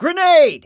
grenade.wav